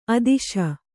♪ adīśa